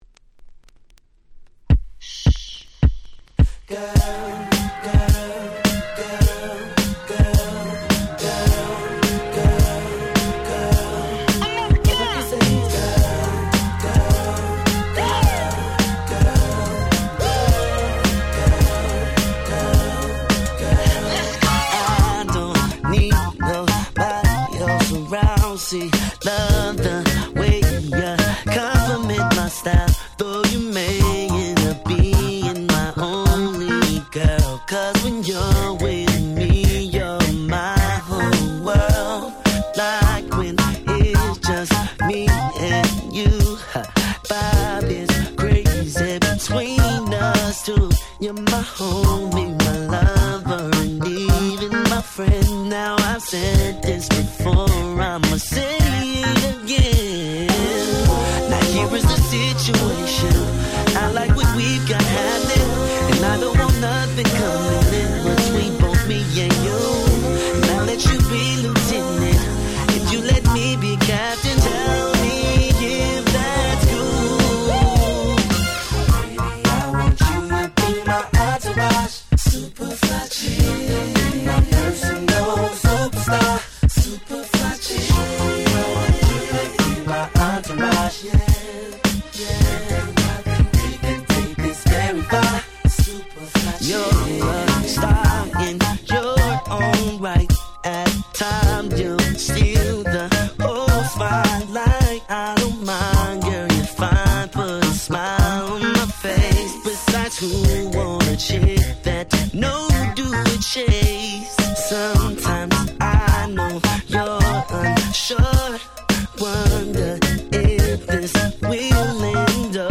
06' Big Hit R&B !!
こう言う「気持ち良い系」のR&Bは時代を問わず使えるので、もし持っていなければ今の内に押さえちゃって下さい！